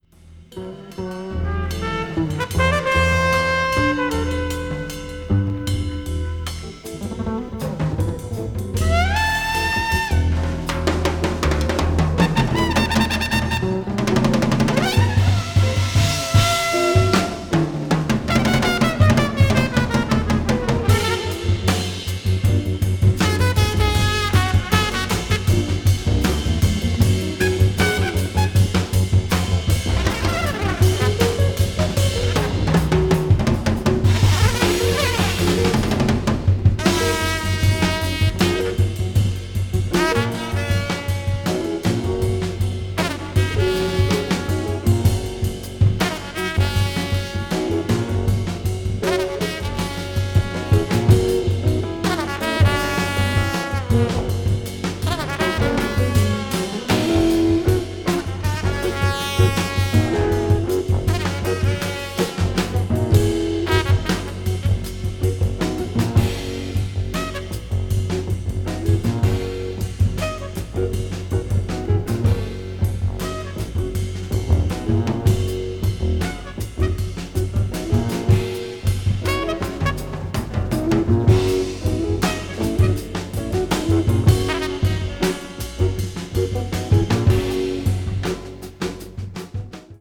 This is a quartet work.
spiritual Latin jazz